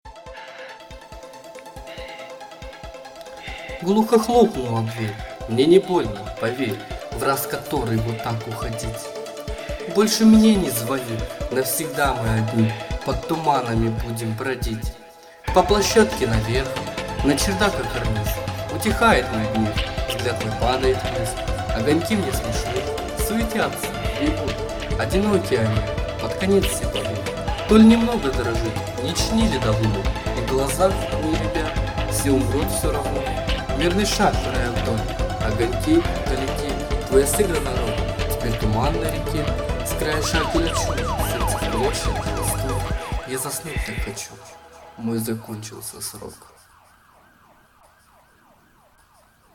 Стих Хлопнула дверь